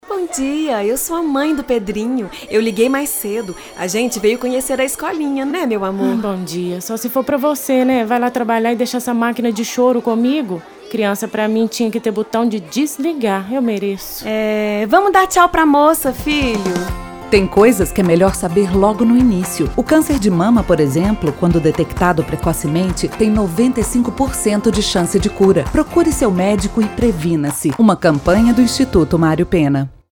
AF-SPOT-OUTUBRO-ROSA.mp3